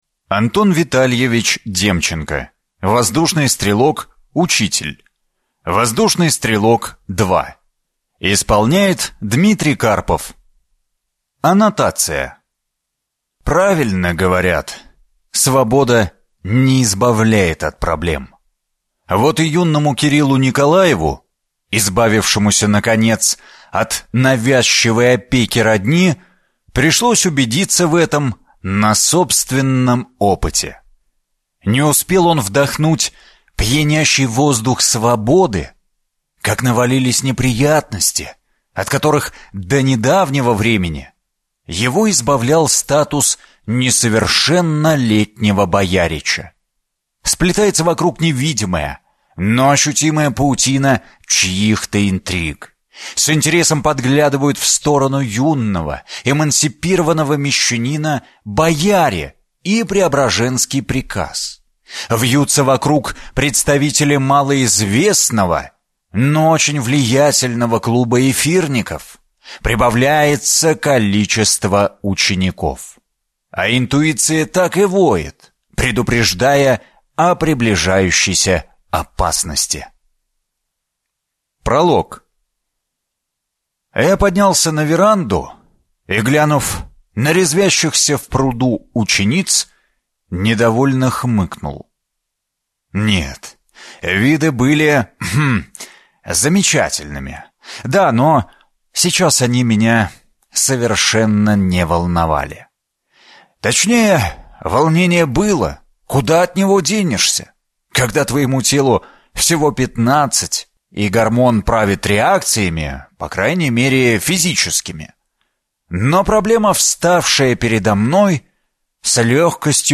Аудиокнига Воздушный стрелок. Учитель | Библиотека аудиокниг